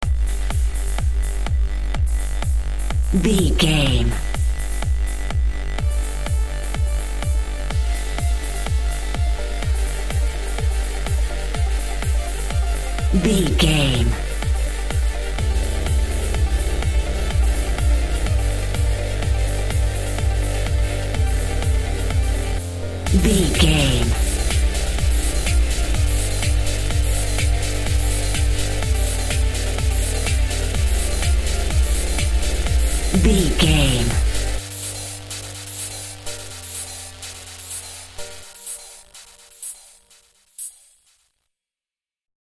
Epic / Action
Fast paced
Aeolian/Minor
Fast
dark
futuristic
groovy
aggressive
house
techno
trance
synth drums
synth leads
synth bass
upbeat